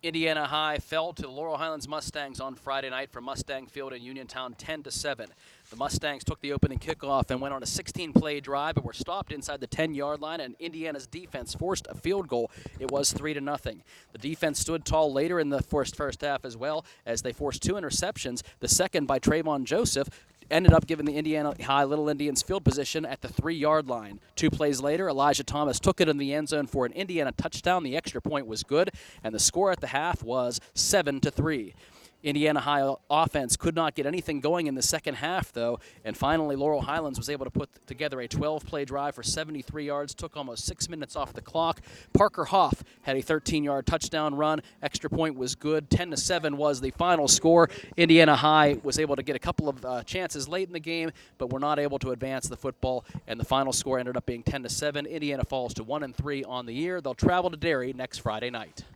hsfb-indiana-vs-laurel-highlands-recap.wav